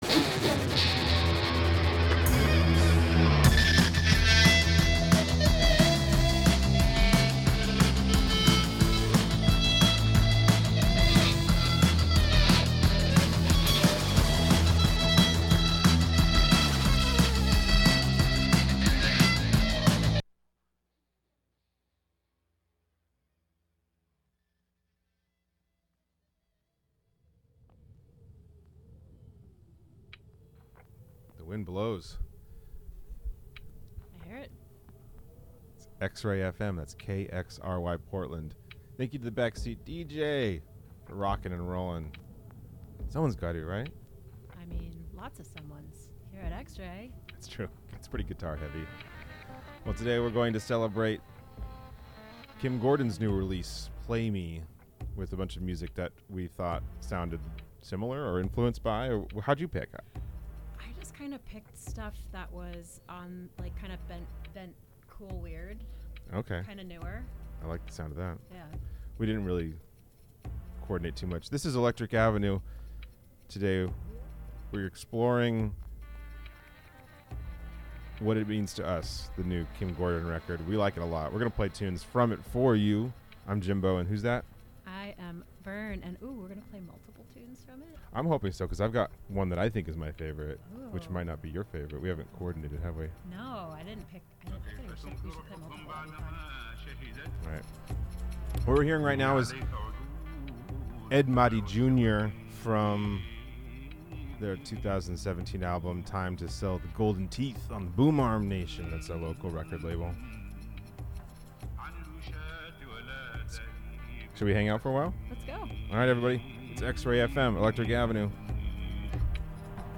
International and domestic jams. It's Electric; It's Eclectic!